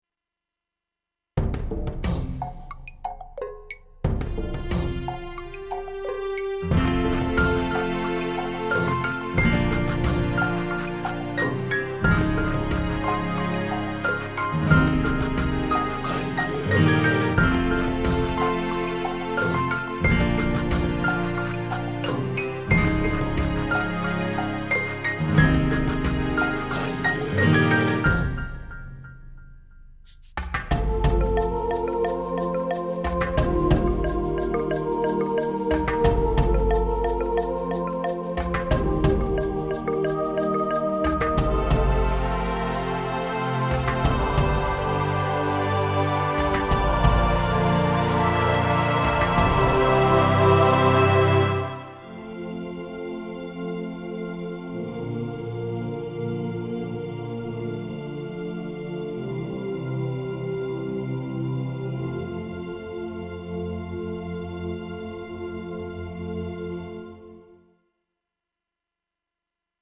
contemporary montage